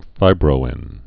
(fībrō-ĭn)